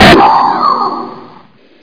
rpgfire.mp3